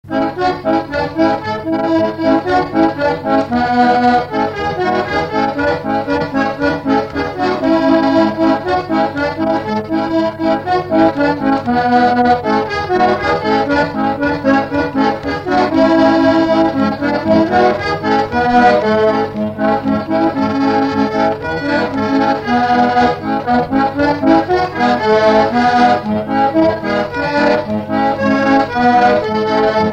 Fox-trot
Instrumental
danse : fox-trot
Pièce musicale inédite